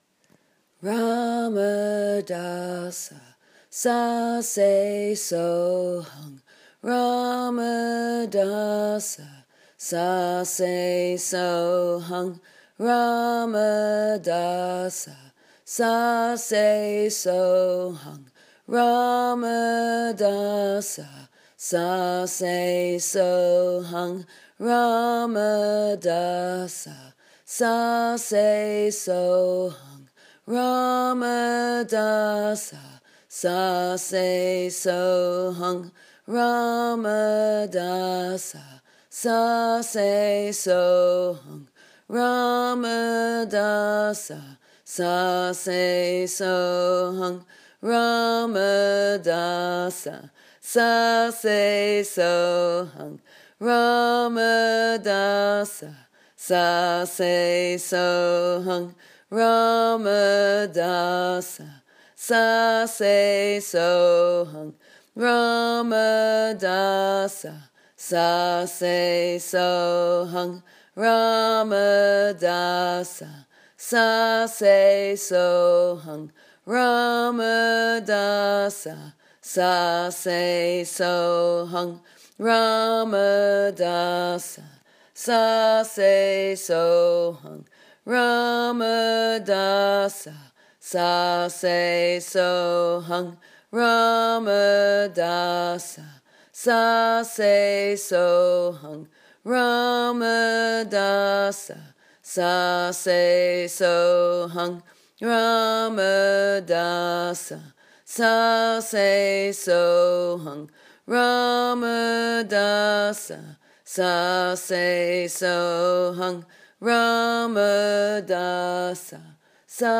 Healing via Mantra